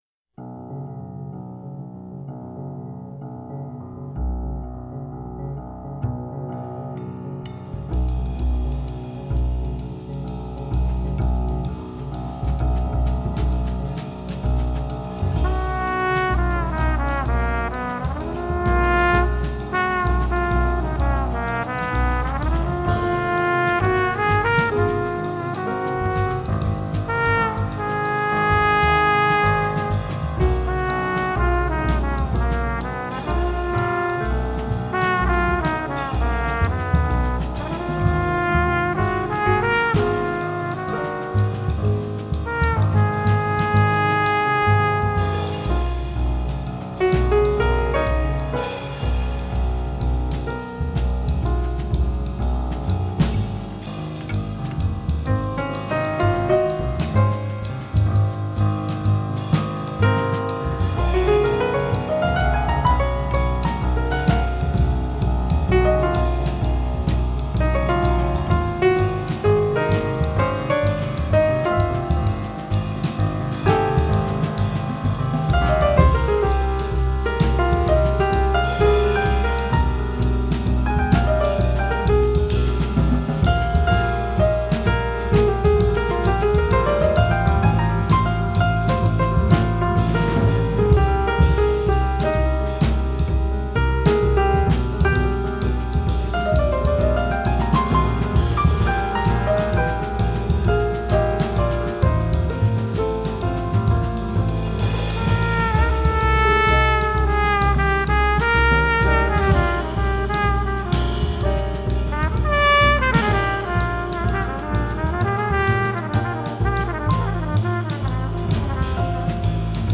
piano
trumpet
bass
drums